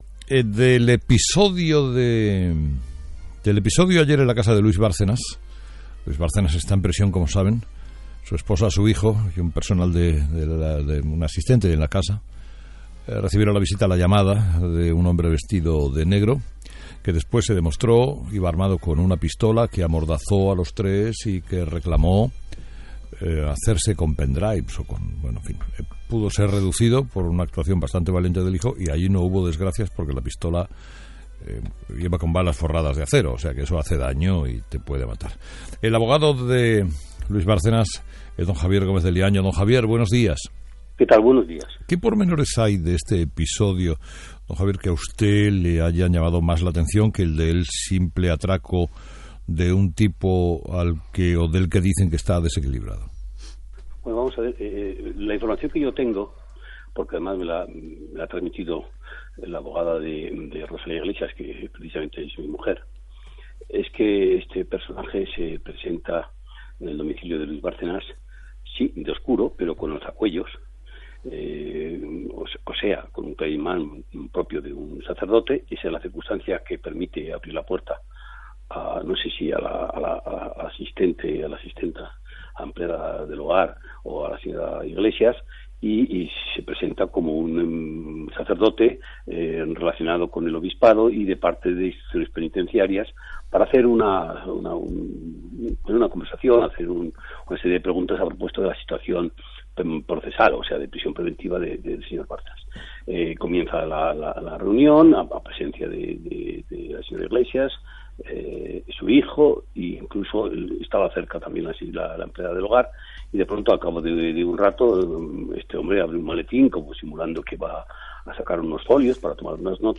Entrevista a Javier Gómez de Liaño.